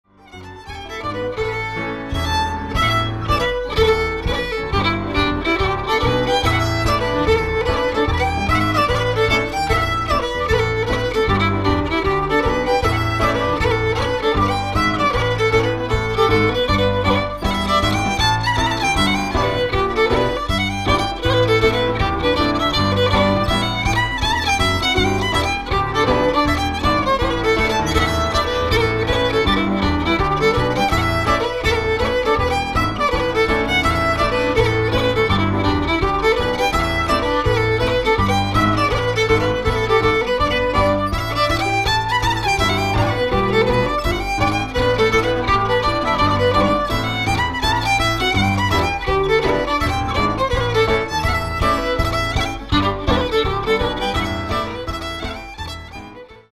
pianist
older Scottish music